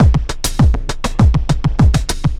pulse tombeat 100bpm 04.wav